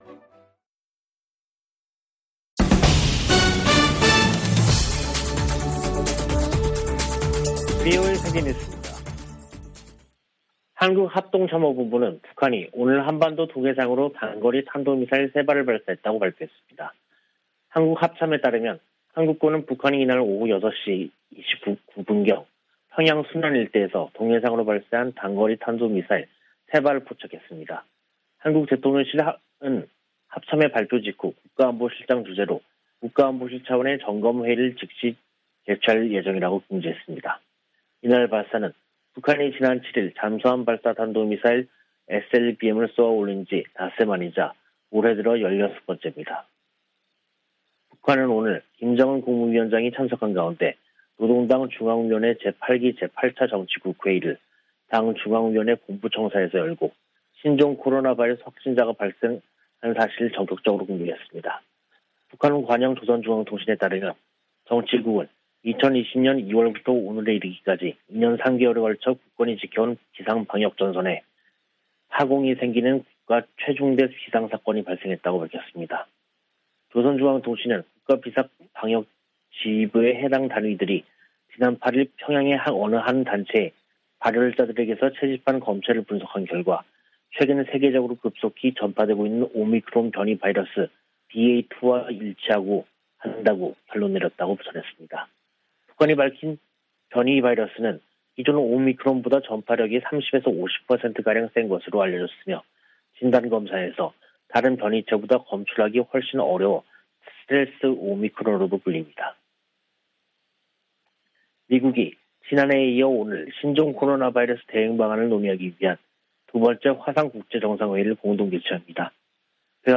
VOA 한국어 간판 뉴스 프로그램 '뉴스 투데이', 2022년 5월 12일 2부 방송입니다. 북한이 또다시 탄도미사일을 발사했습니다.